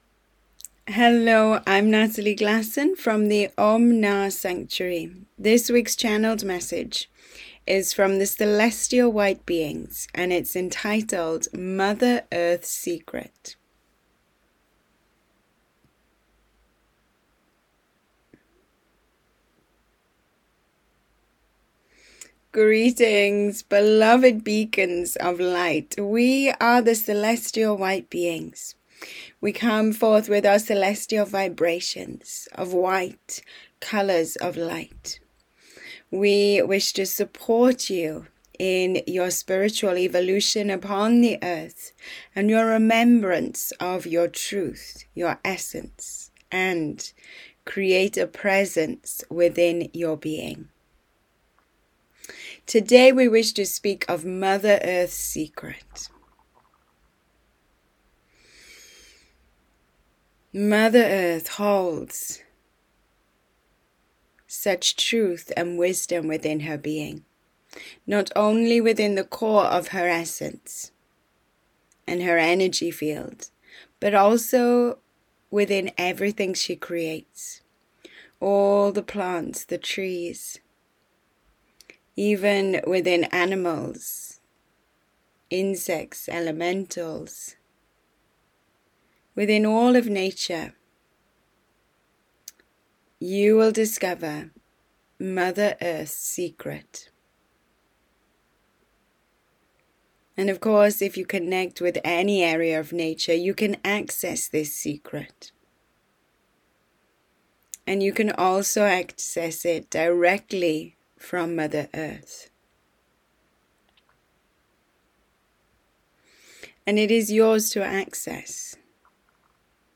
Channeled Message